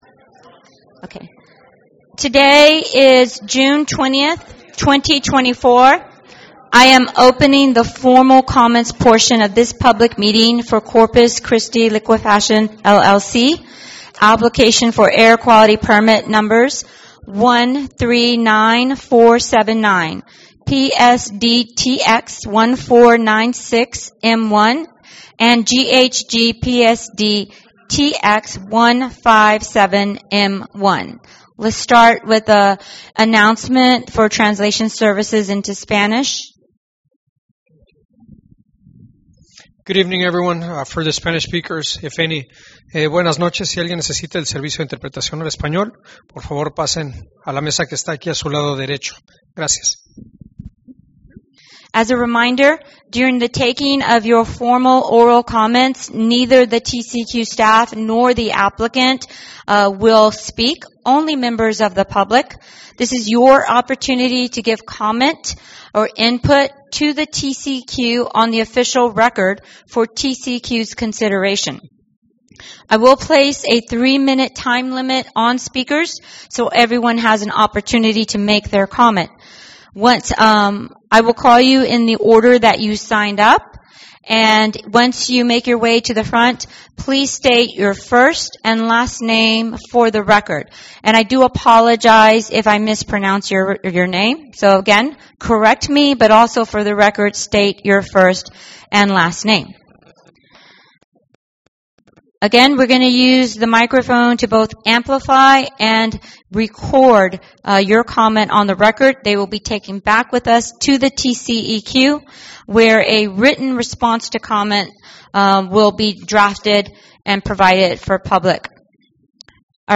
2024-1197-AIR Public Meeting Audio